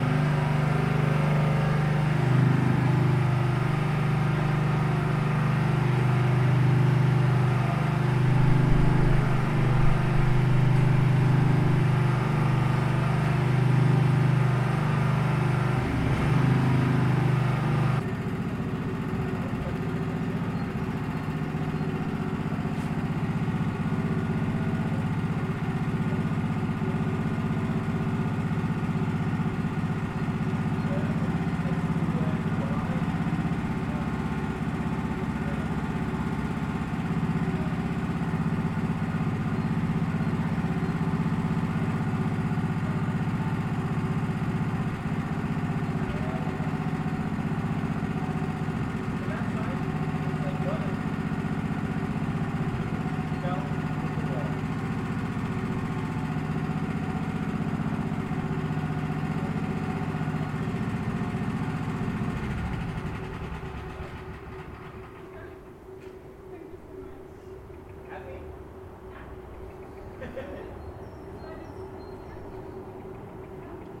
harrowing.mp3